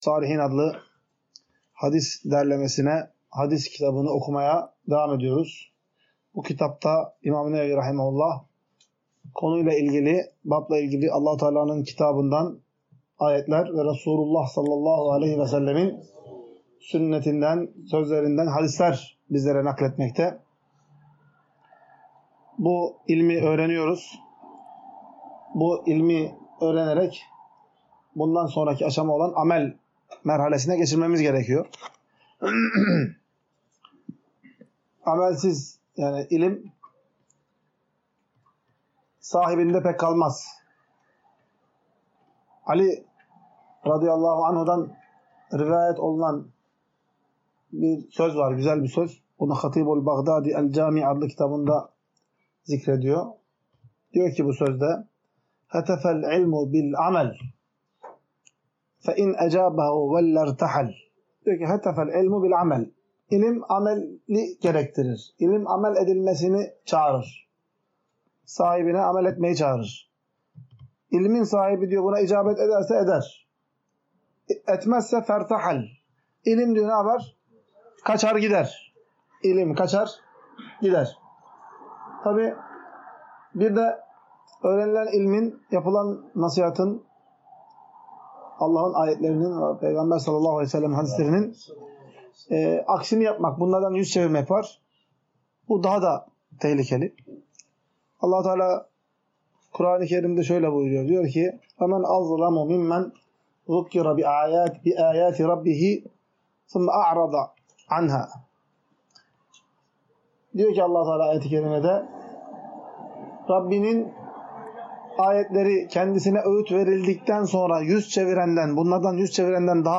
Ders - 7.